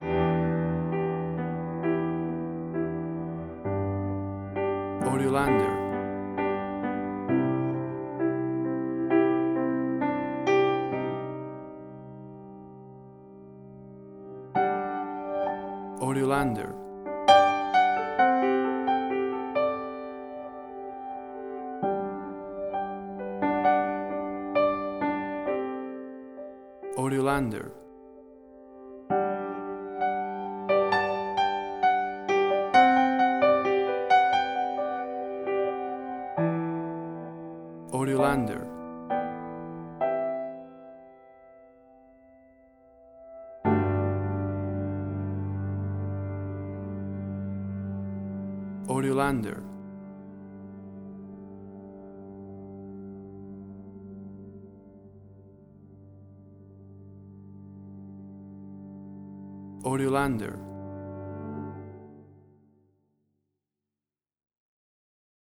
WAV Sample Rate: 16-Bit stereo, 44.1 kHz
Tempo (BPM): 65